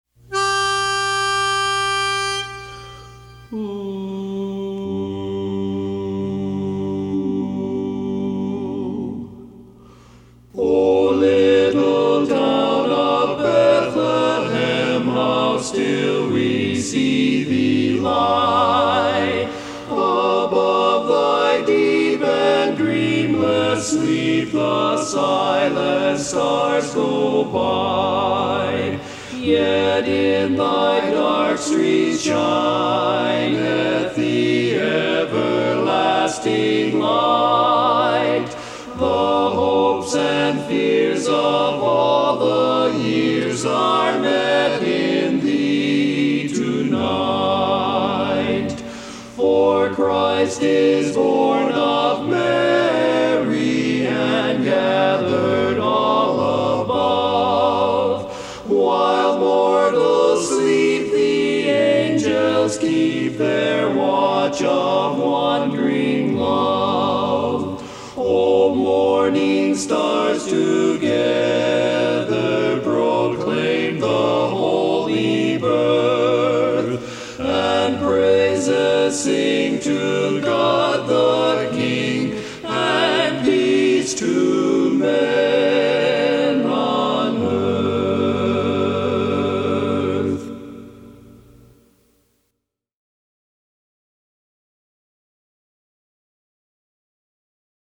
Barbershop
Bass